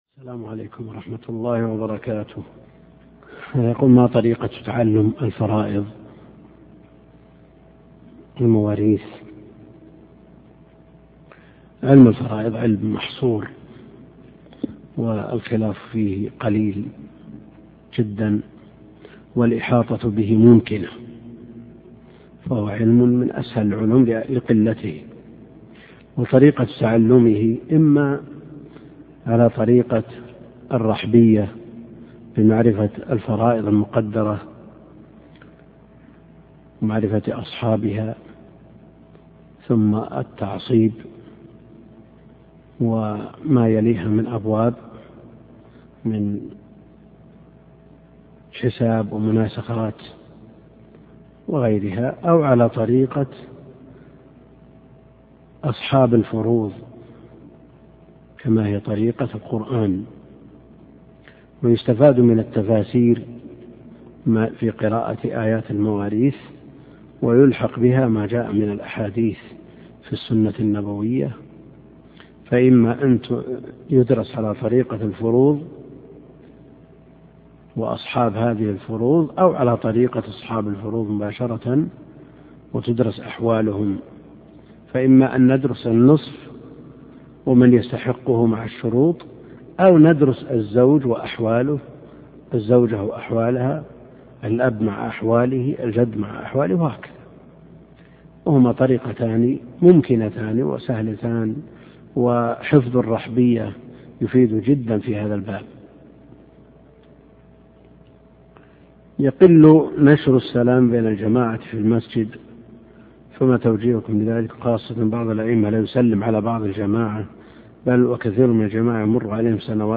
الدرس (7) شرح تنقيح الأنظار لابن الوزير